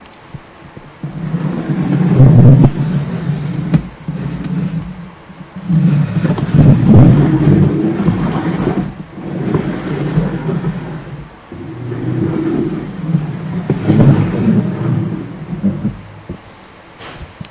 椅子
描述：椅子被刮过硬木地板的录音。